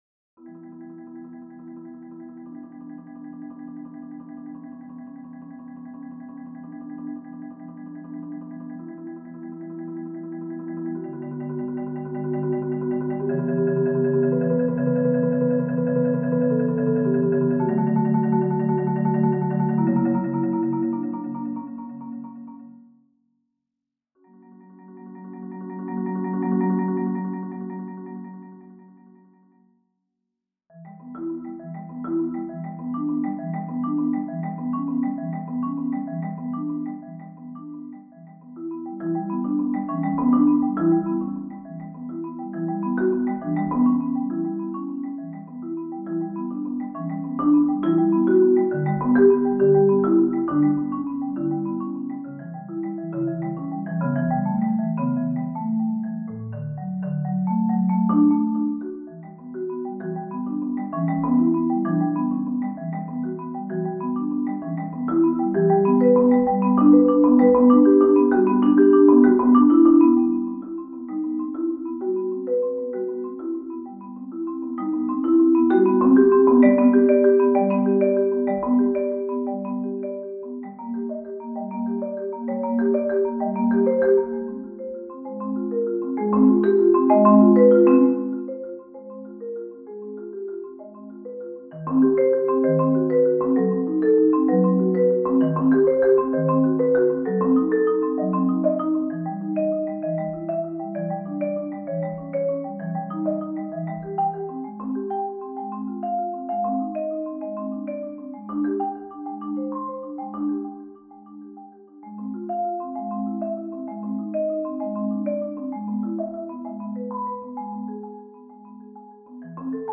Solo – Marimba